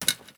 padlock_wiggle_03.wav